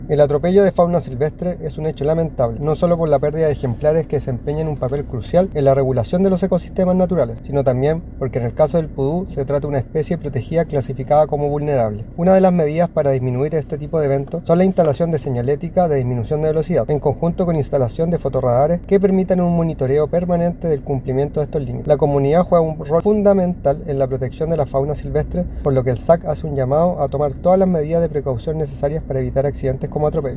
De igual manera, el Director Regional de Servicio Agrícola y Ganadero, Francisco Briones, hizo un llamado a tomar medidas para evitar estos accidentes.
cuna-director-sag.mp3